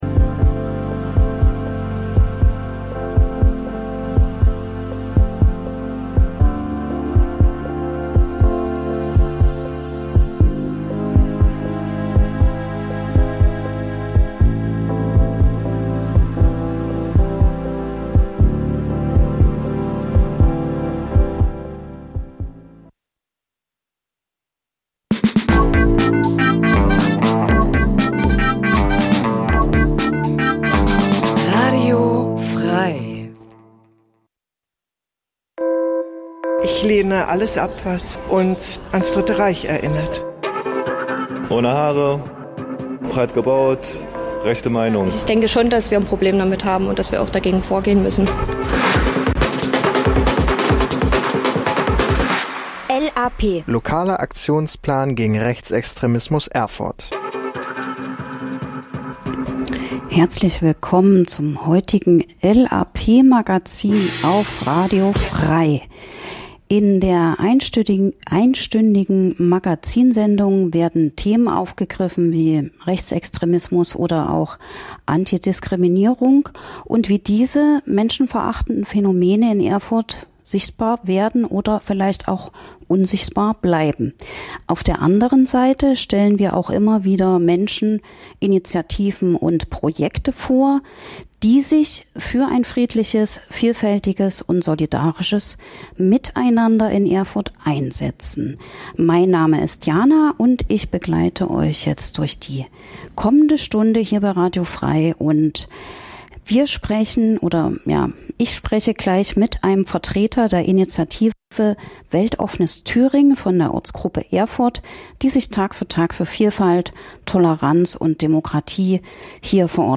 - Interviews zu aktuellen Themen
Musikrubrik "Coole Cover"